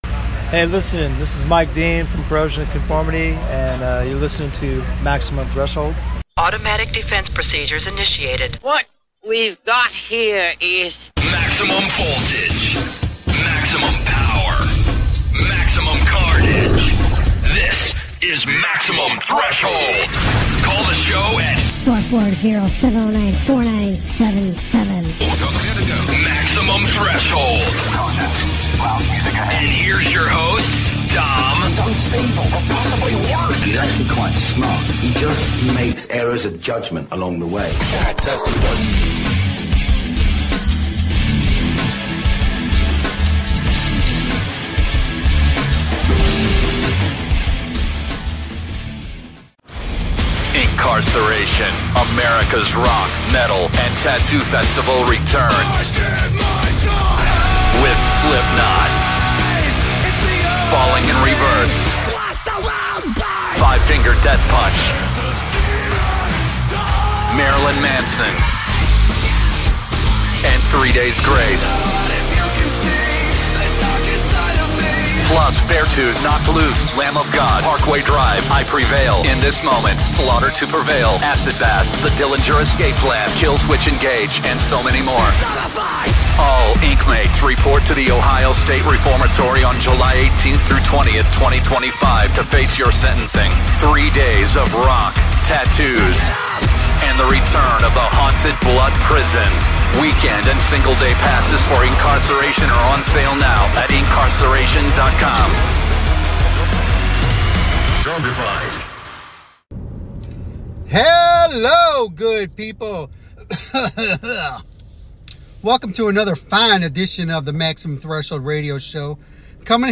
So this interview is really interesting.